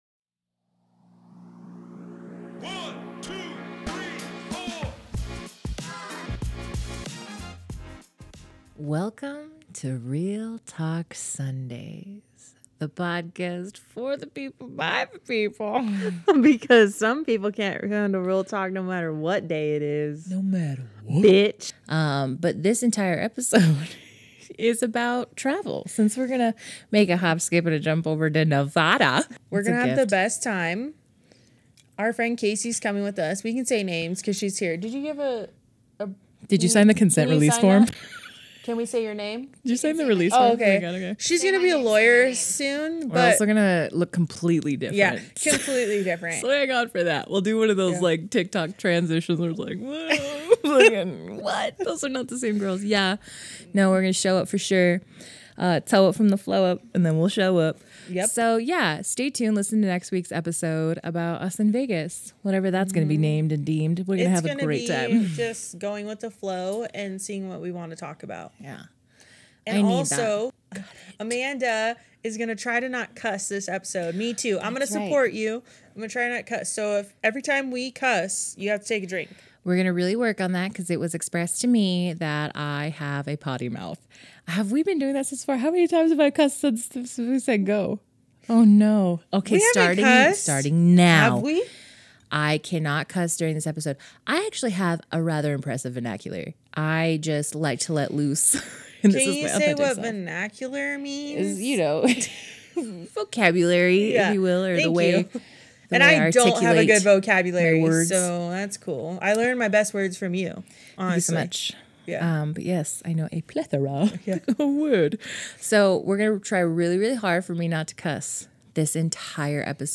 Throughout the episode, the hosts make a valiant effort to keep things clean, avoiding profanity whenever possible... the challenge is clearly proving too difficult.